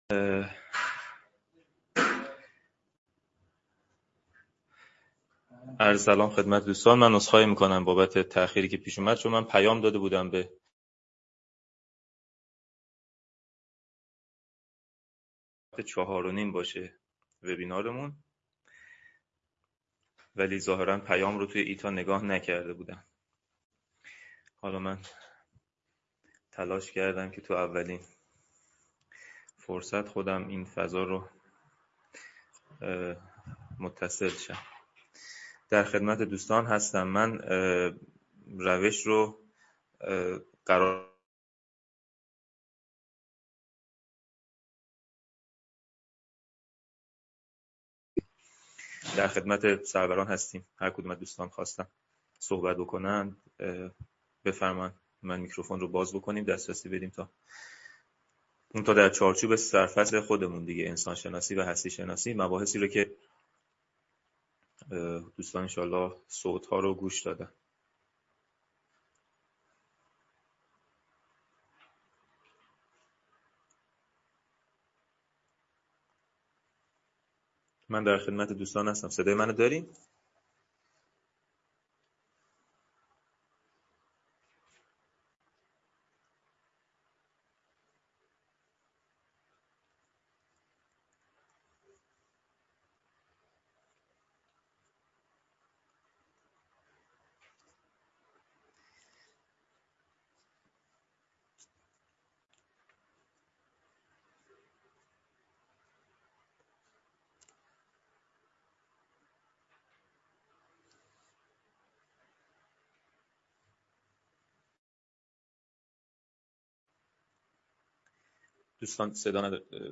انسان شناسی و جهان شناسی - جلسه-پرسش-و-پاسخ